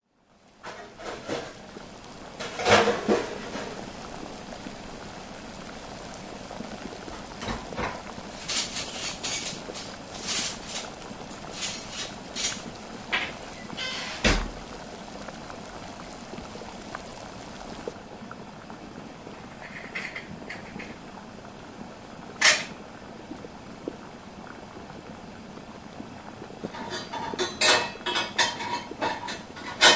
Kitchen sounds
Busy Kitchen with sounds of saucepans,peeling etc
KitchenBusy.mp3